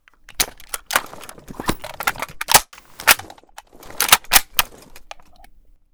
l85_reload_empty.ogg